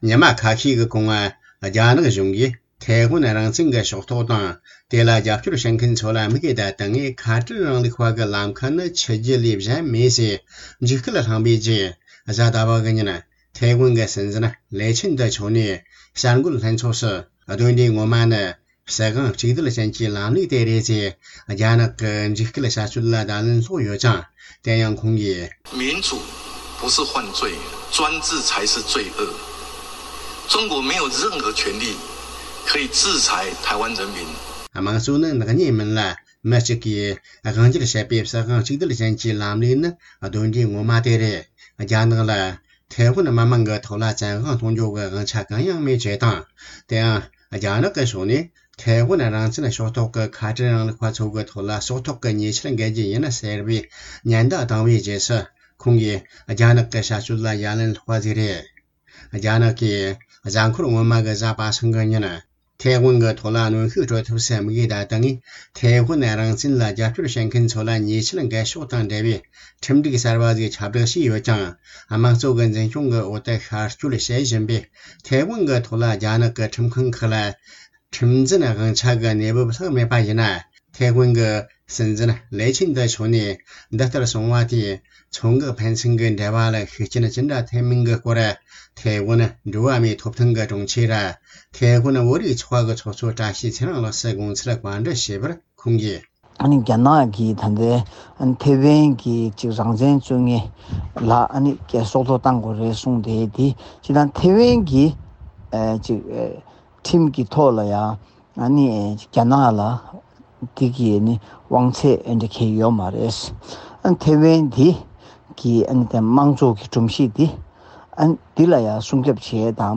སྒྲ་ལྡན་གསར་འགྱུར། སྒྲ་ཕབ་ལེན།
ཐའེ་ཝན་གྱི་སྲིད་འཛིན་ལའེ་ཆིན་ཏེས་སྲིད་དབང་གཅིག་སྡུད་ཅན་གྱི་ལམ་ལུགས་ནི་གདོན་འདྲེ་ངོ་མ་དེ་རེད་ཅེས་གསུངས་ཡོད་པའི་ཐད་འབྲེལ་ཡོད་ཉམས་ཞིབ་པའི་བསམ་ཚུལ་བསྡུ་ལེན་གྱི་ཚུལ་དུ་རྒྱང་སྲིང་བྱས་པར་གསན་རོགས་ཞུ།